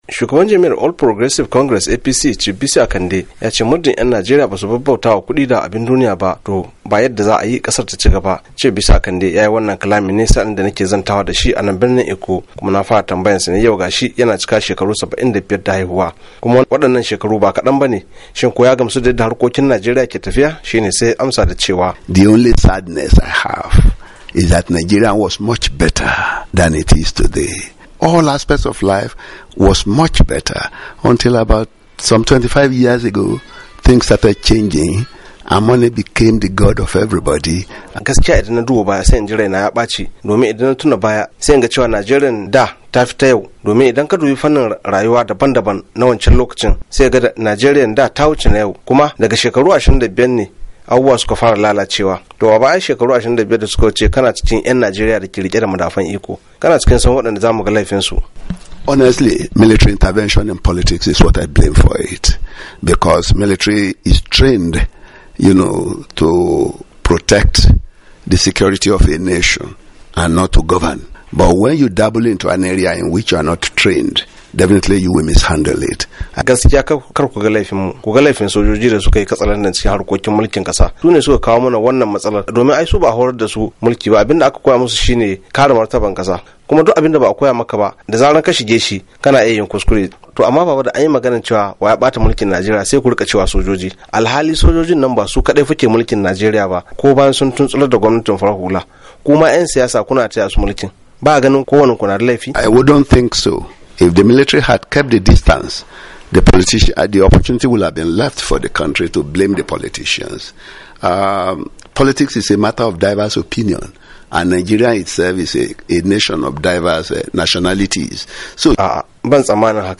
A zantawar da ya yi da wakilin Muryar Amurka yayin da ya cika shekaru 75 da haifuwa Chief Bisi Akande shugaban jam'iyyar APC na kasa ya ce Najeriya ba zata cigaba ba muddin 'yan kasar suka cigaba da bautawa kudi da abun duniya.
Ga cikakken rahoto.